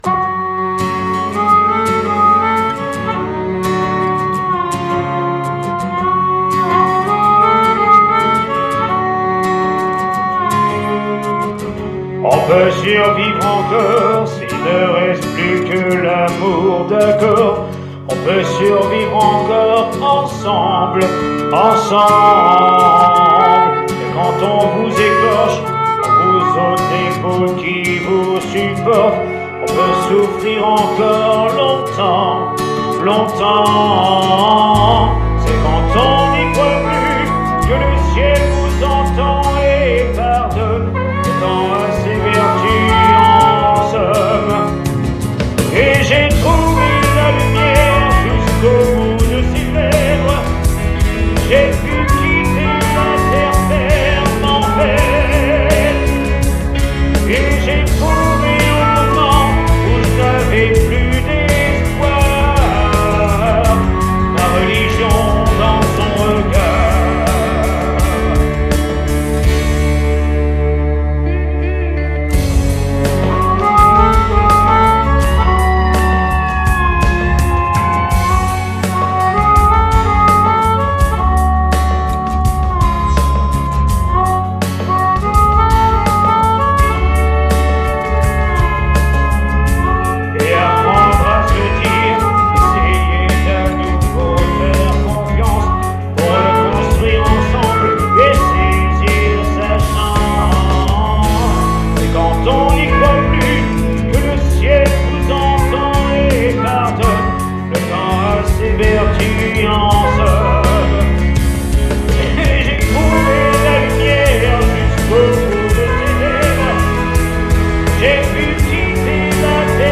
SOIREES BLUES-ROCK RETROSPECTIVE
DUO CHANT/HARMONICA
maquettes